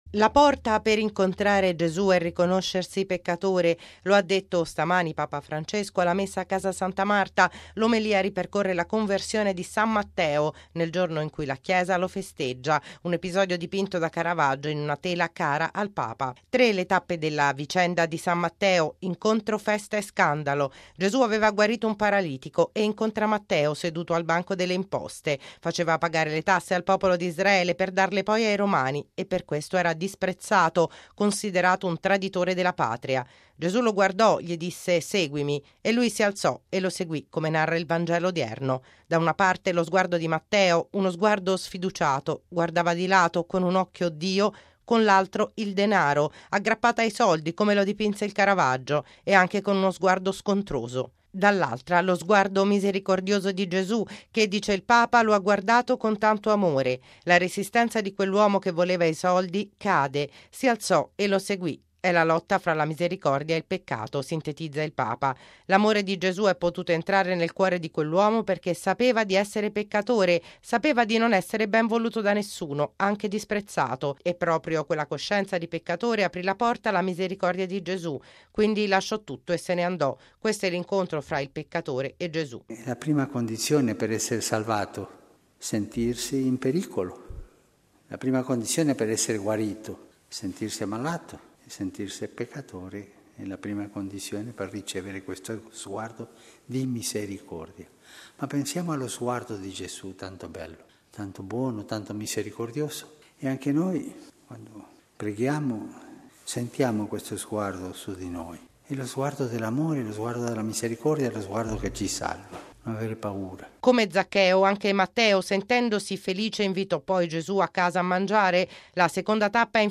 Lo ha detto stamani Papa Francesco alla Messa a Casa Santa Marta. L’omelia ripercorre la conversione di San Matteo, nel giorno in cui la Chiesa lo festeggia.